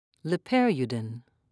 (le-pir'u-din)